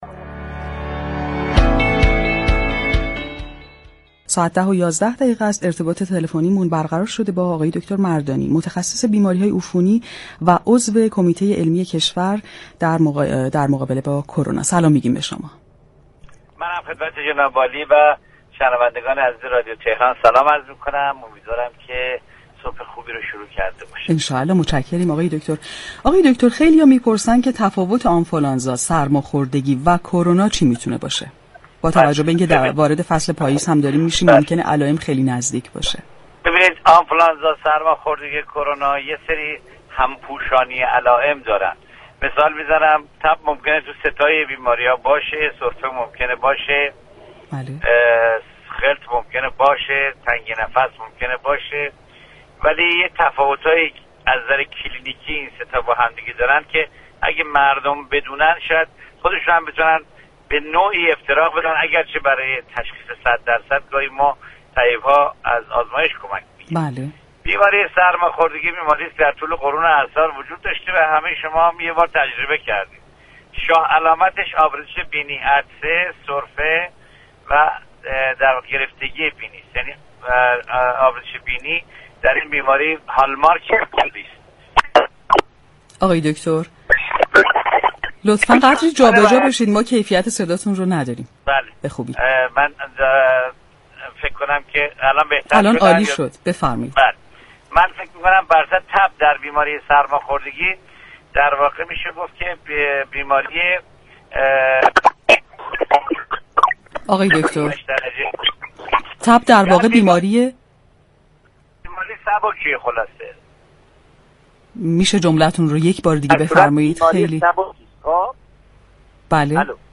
در گفتگو با تهران كلینیك رادیو تهران